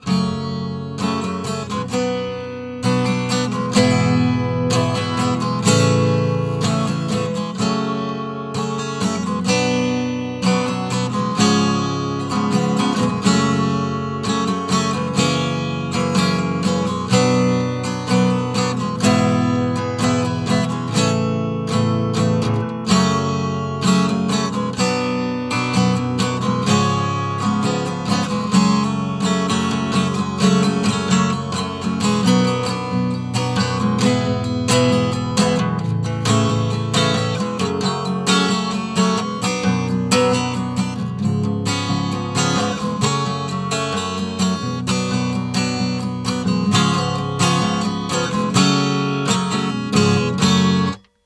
Вот все которые сталкеры играют у костра.